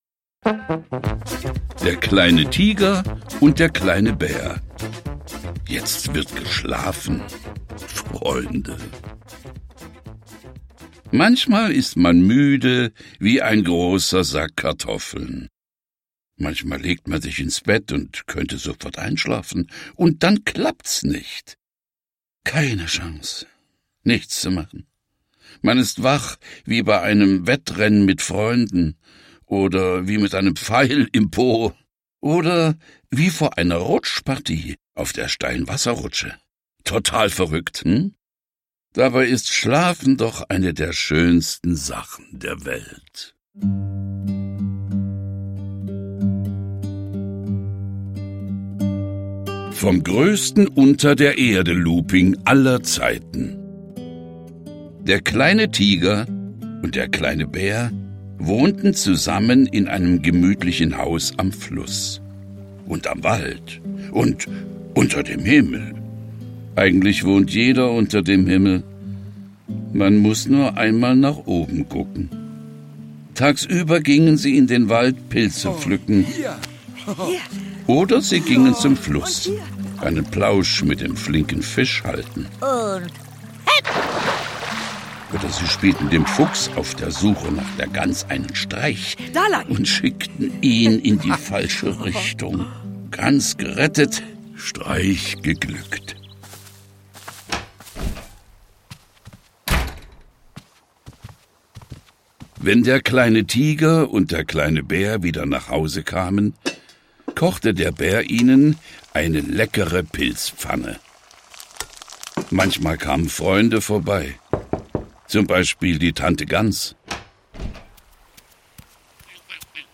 Hörbuch: Nach einer Figurenwelt von Janosch.